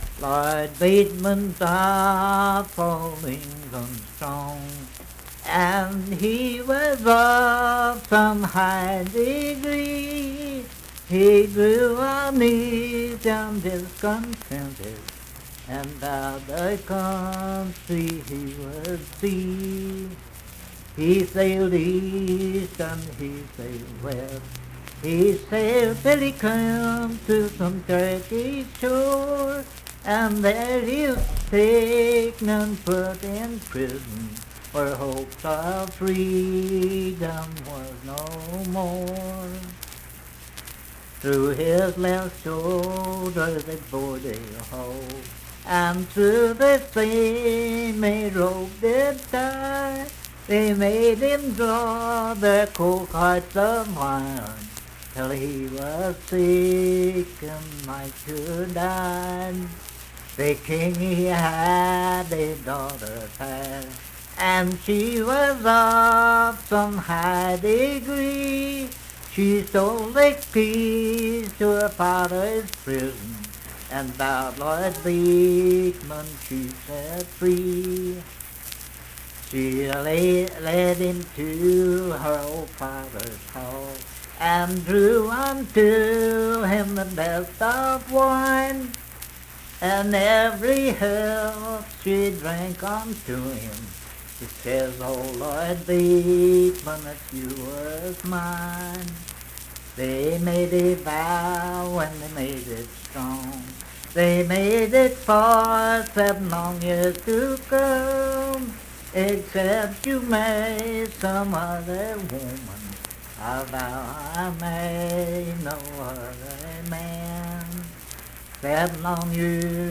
Unaccompanied vocal music
Voice (sung)
Huntington (W. Va.), Cabell County (W. Va.)